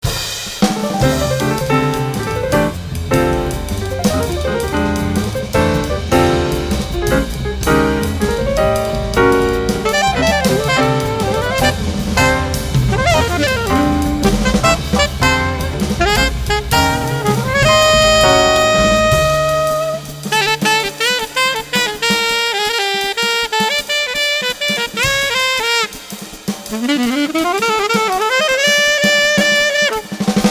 sax alto
batteria
Influenze blues
prevale uno spirito hard-bop retrò e moderno al tempo stesso